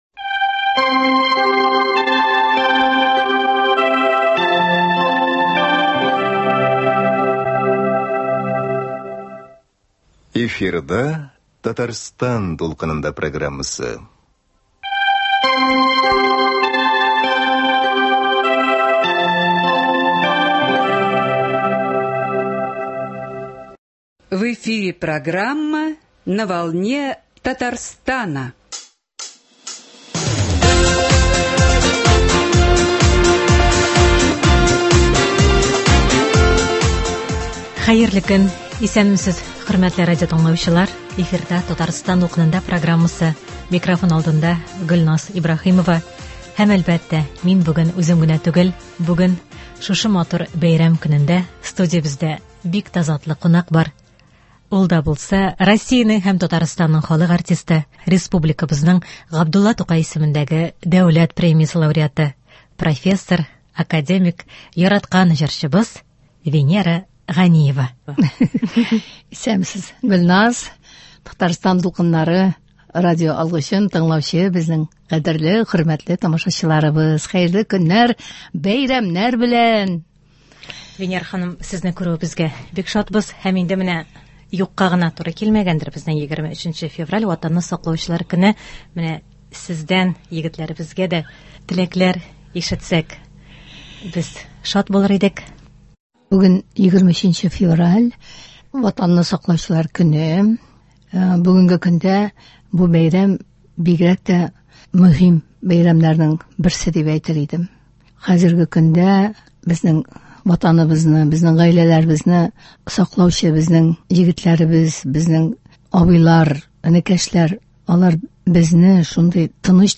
Студиябез кунагы — Россиянең һәм Татарстанның халык артисты, республиканың Габдулла Тукай исемендәге дәүләт премиясе лауреаты, профессор, академик, җырчы